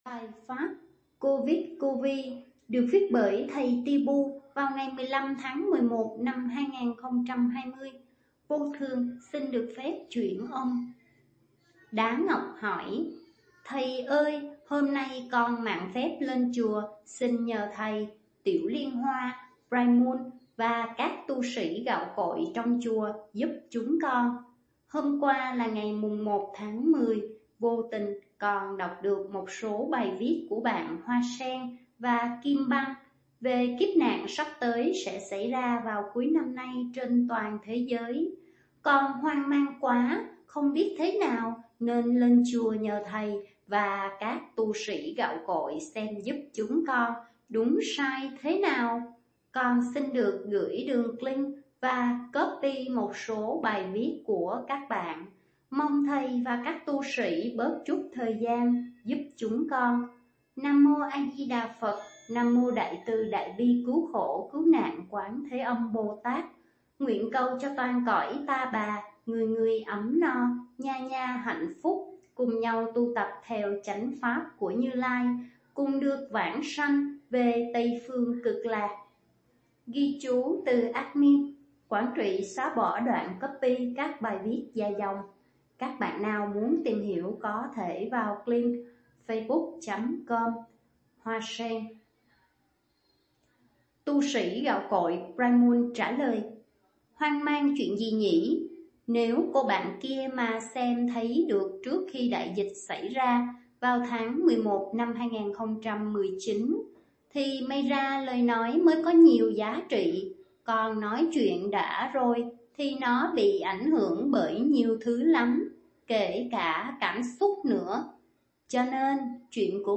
chuyển âm